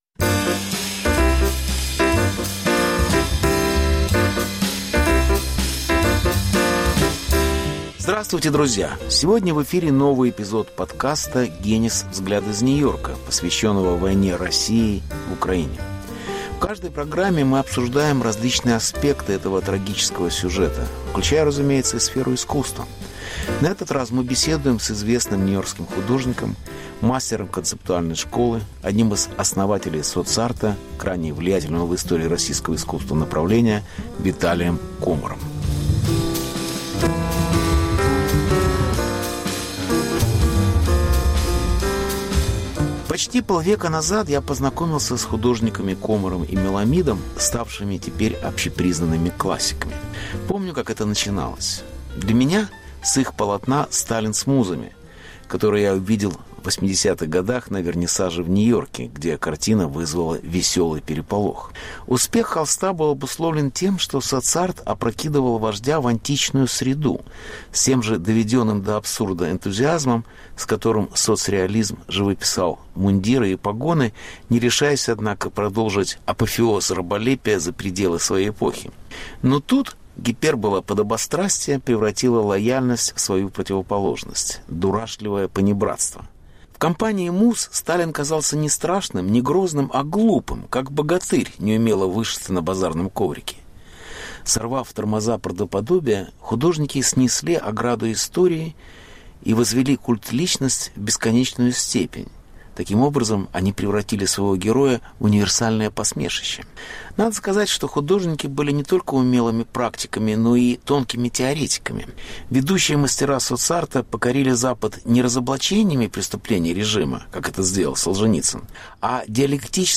Ведущие мастера Соц-арта покорили Запад не разоблачениями преступлений режима, как это сделал Солженицын, а диалектической трактовкой советского опыта. Беседа с художником Виталием Комаром. Повтор эфира от 13 ноября 2022 года.